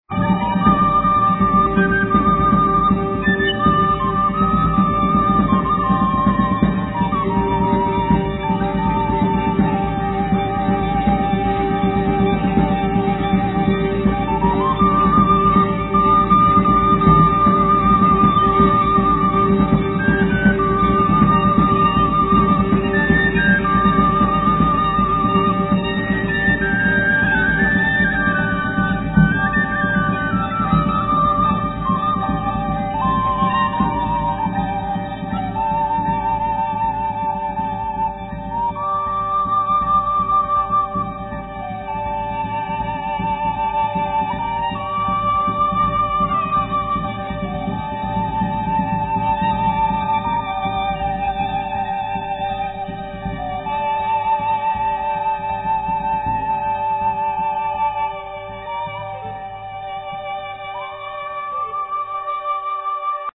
Violin, Viola
Didjeridoo, Zither, Tuned glass
Drums
Guitar